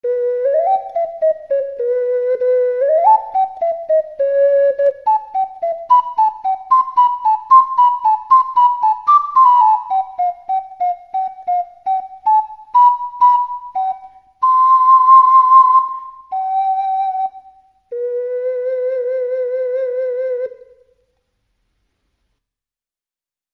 音符をクリックすると管理人のデモ演奏が聴けます。
だいぶリバーブに助けられちゃってます。
この音の高さがオカリナのイメージに一番近いと思います。
上のピッコロＣより１オクターブ下。
alto-C-oca-samplemusic.mp3